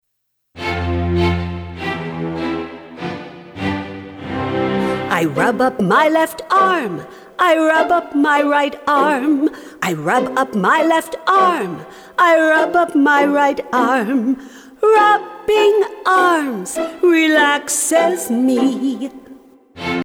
calming and alerting songs
CALMING SONGS ALERTING SONGS